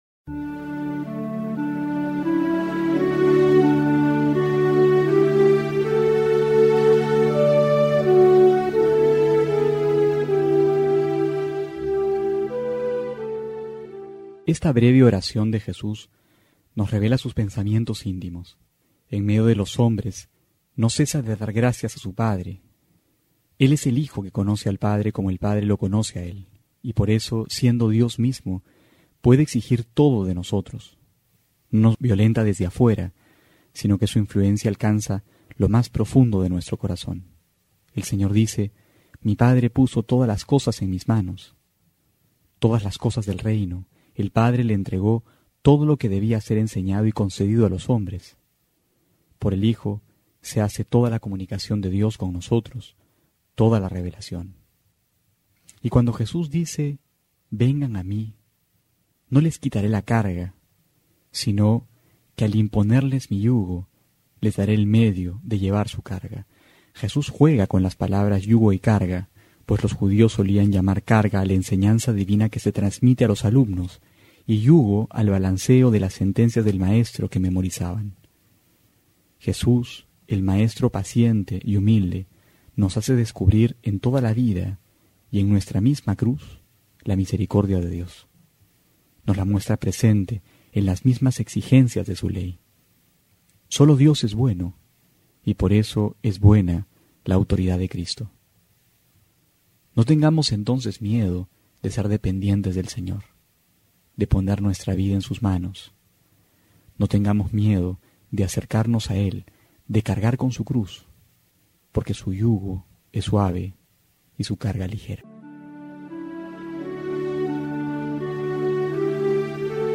Homilía para hoy:
julio18-12homilia.mp3